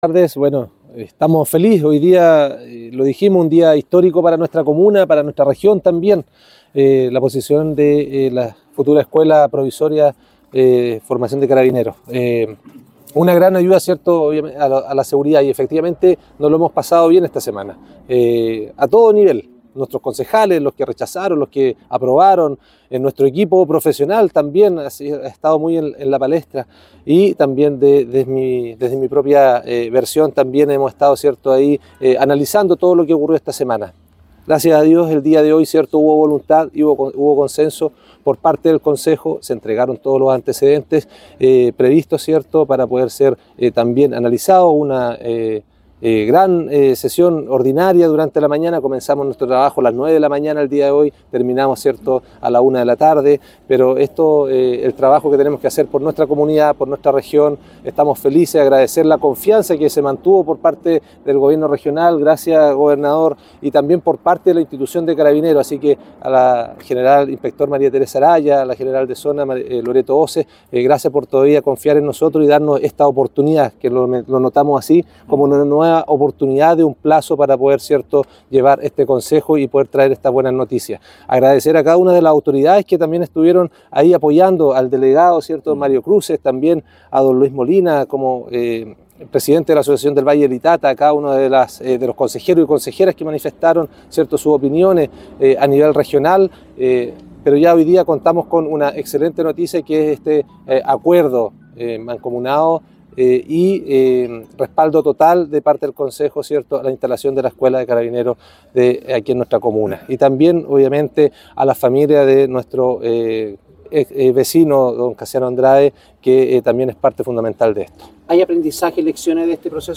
alcalde-Redlich-OK.mp3